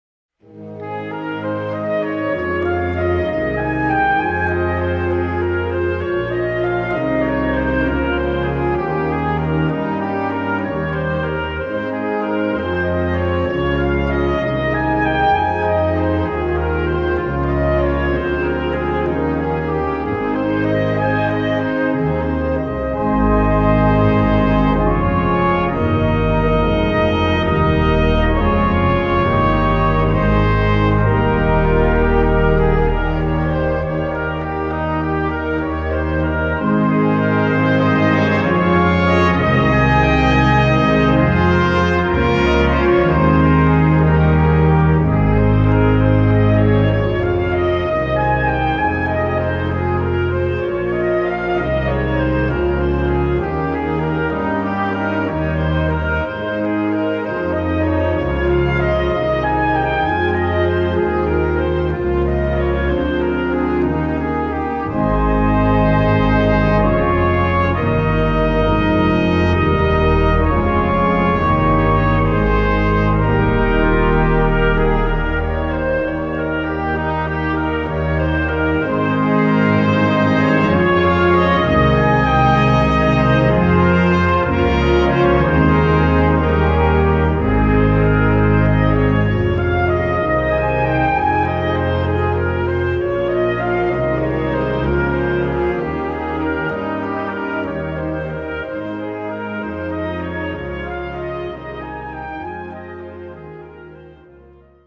Gattung: Solo für Orgel und Blasorchester
Besetzung: Blasorchester